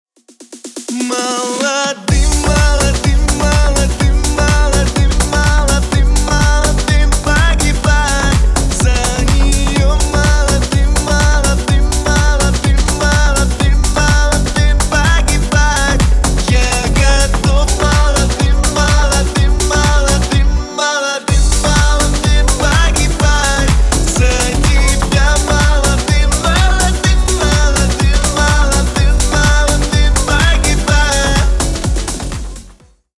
• Качество: 320 kbps, Stereo
Ремикс
Поп Музыка